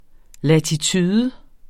latitude substantiv, fælleskøn Udtale [ latiˈtyːðə ] Oprindelse fra fransk latitude af latin latitudo 'bredde' Betydninger 1.